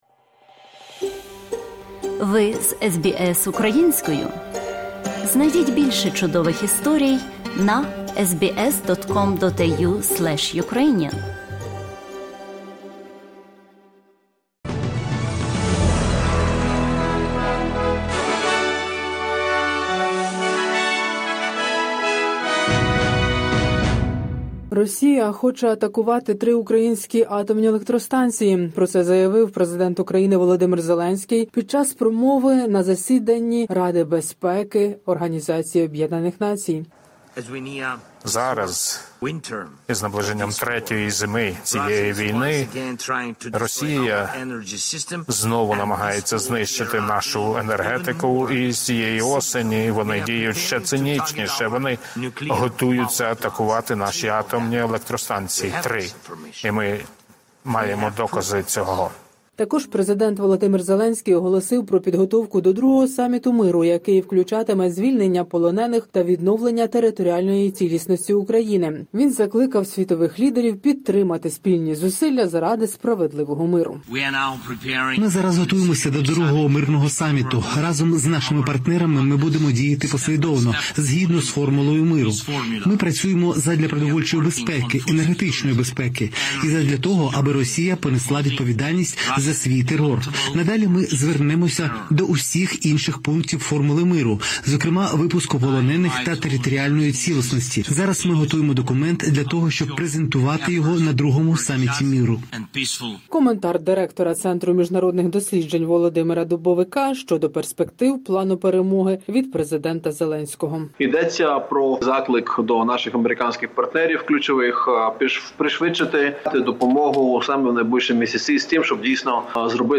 Огляд новин з України за 27 вересня 2024 року.